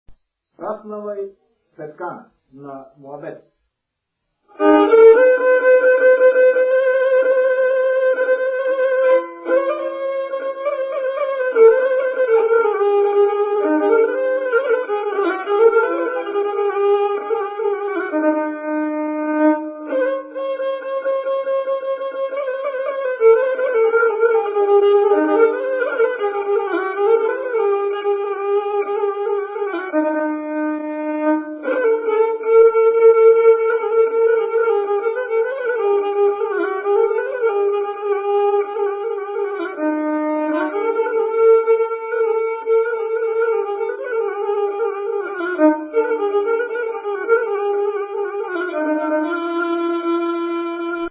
музикална класификация Инструментал
размер Безмензурна
фактура Двугласна
начин на изпълнение Солово изпълнение на гъдулка
битова функция На моабет
фолклорна област Югоизточна България (Източна Тракия с Подбалкана и Средна гора)
начин на записване Магнетофонна лента